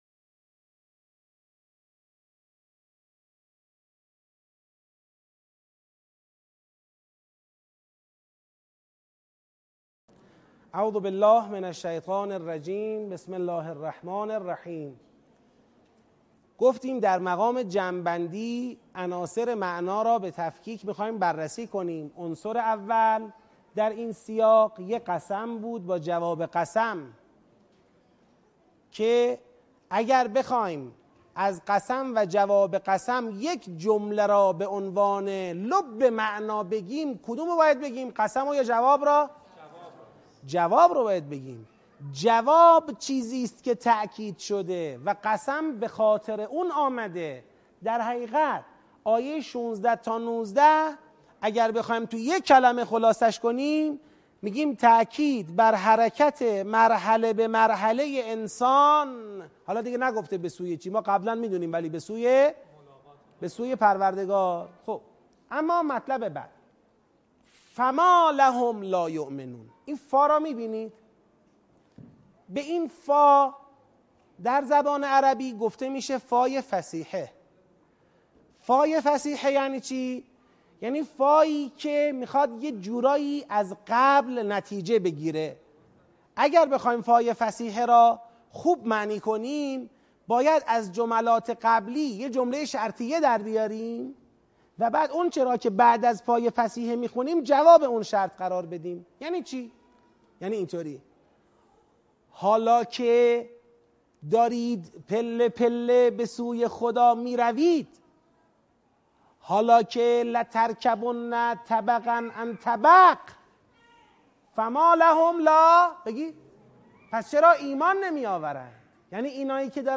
آموزش تدبر در سوره انشقاق - بخش سوم